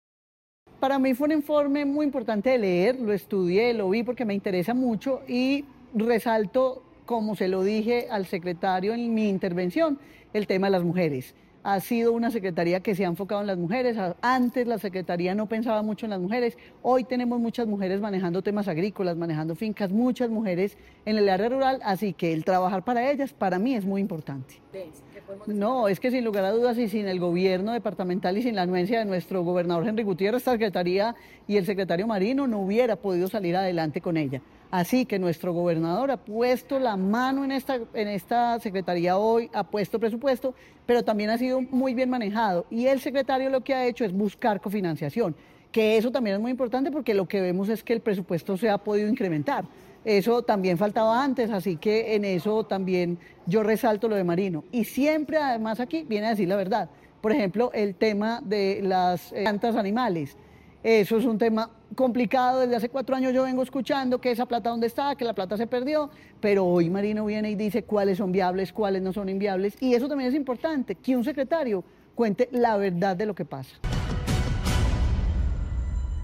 María Isabel Gaviria, diputada de Caldas.